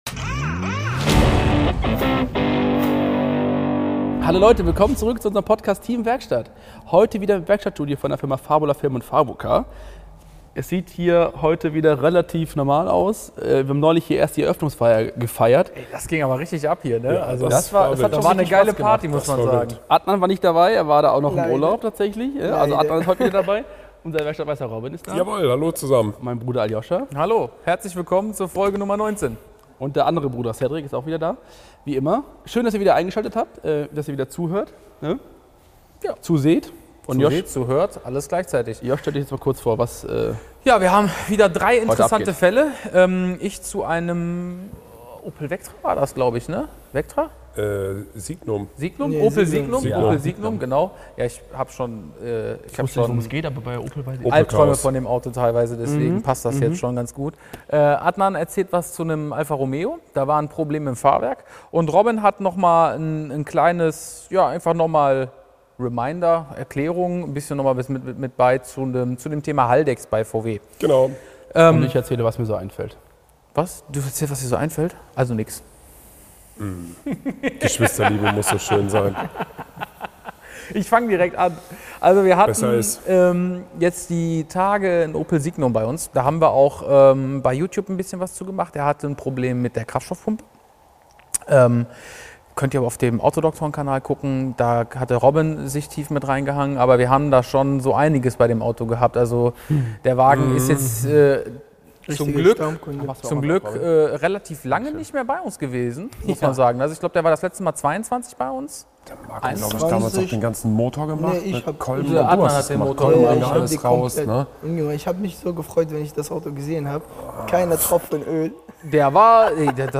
#19 Bremskreise ohne Druck ~ TEAM WERKSTATT | Der Feierabend-Talk aus der Werkstatt der Autodoktoren Podcast